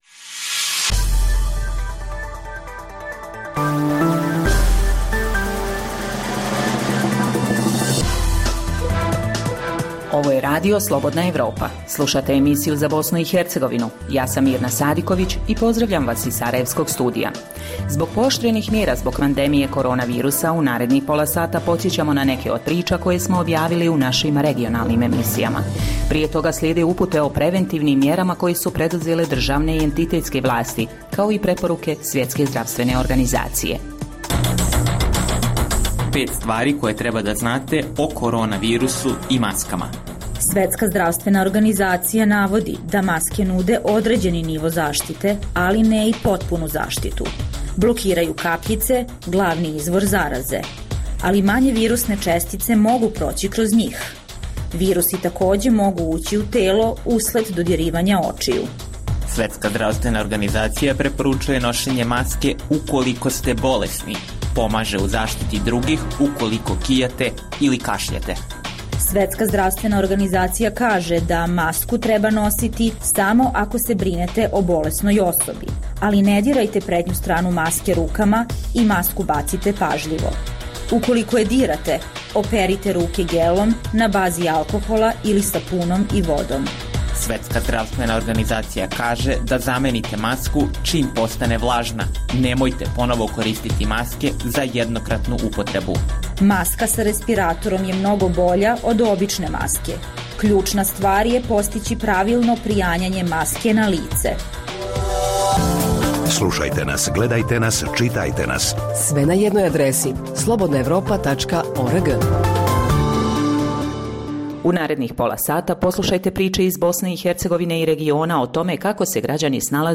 Emisija namijenjena slušaocima u Bosni i Hercegovini. Sadrži lokalne, regionalne i vijesti iz svijeta, tematske priloge o aktuelnim dešavanjima.